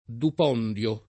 vai all'elenco alfabetico delle voci ingrandisci il carattere 100% rimpicciolisci il carattere stampa invia tramite posta elettronica codividi su Facebook dupondio [ dup 0 nd L o ] o dipondio s. m. (numism.); pl. ‑di